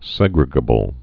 (sĕgrĭ-gə-bəl)